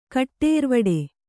♪ kaṭṭērvaḍe